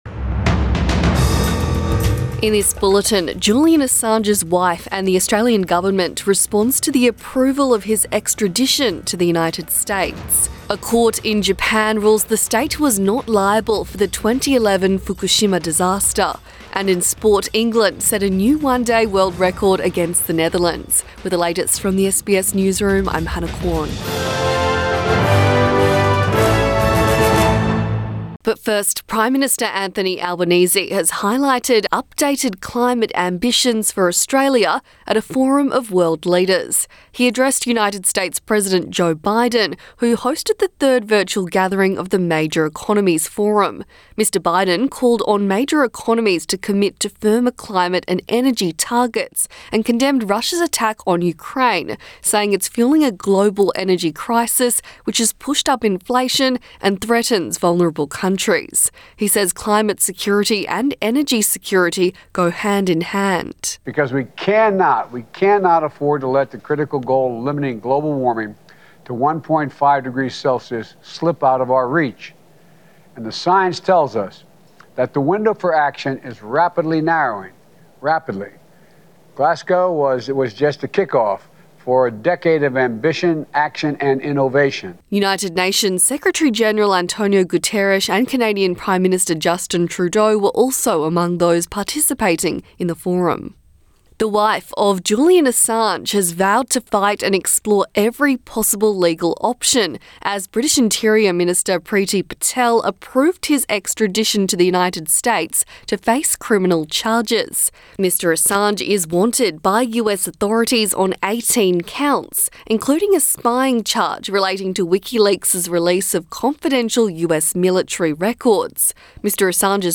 AM bulletin 18 June 2022